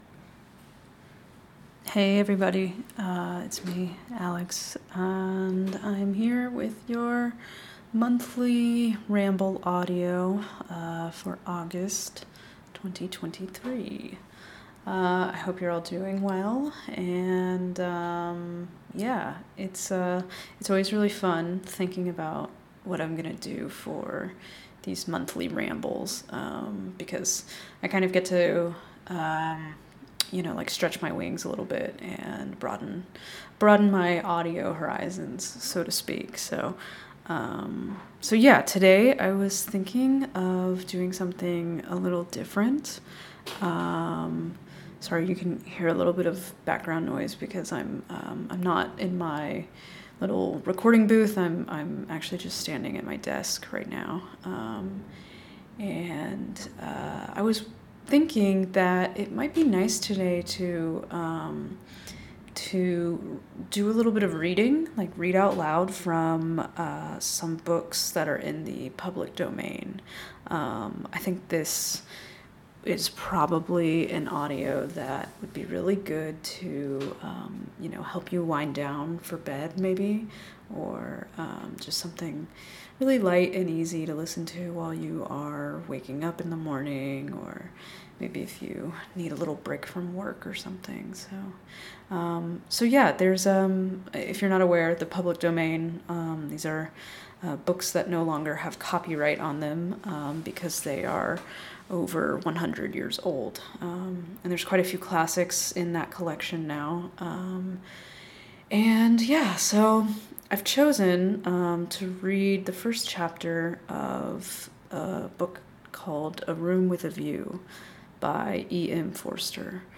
Instead of a true 'ramble' I wondered what it might be like to read aloud from a book in public domain. This audio would be great for winding down before bed I think...
also of note, I left all of my outtakes in this audio for your enjoyment lol there were a lot of unexpected Italian and French words that got me.